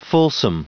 Prononciation du mot fulsome en anglais (fichier audio)
Prononciation du mot : fulsome